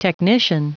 Prononciation du mot technician en anglais (fichier audio)
technician.wav